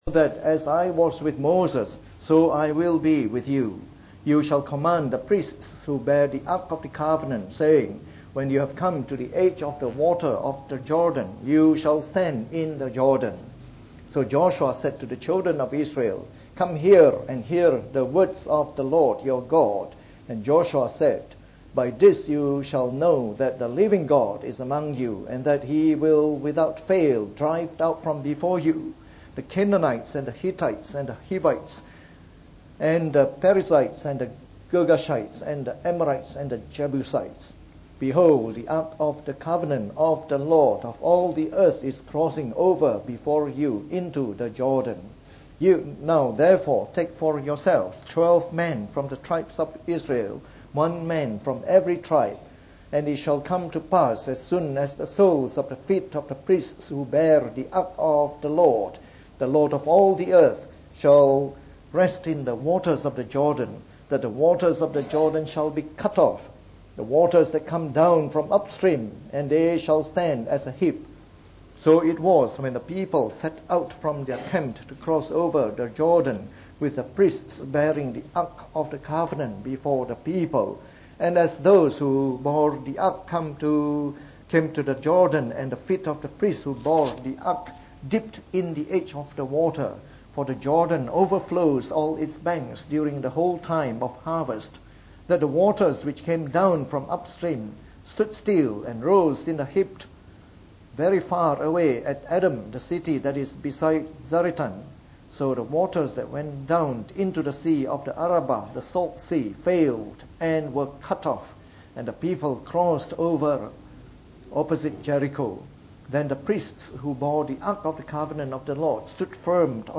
From our series on the Book of Joshua delivered in the Morning Service.